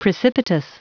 Prononciation du mot precipitous en anglais (fichier audio)
Prononciation du mot : precipitous